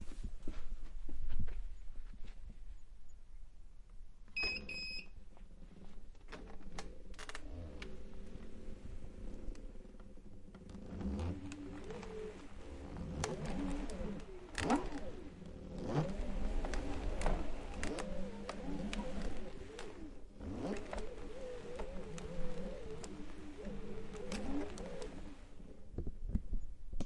冰箱湿度
描述：普通家用冰箱的压缩机发出的放大的嗡嗡声。用MAudio Microtrak II.
Tag: 申请iance 无人驾驶飞机 冰箱